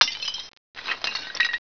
pottery_workshop.wav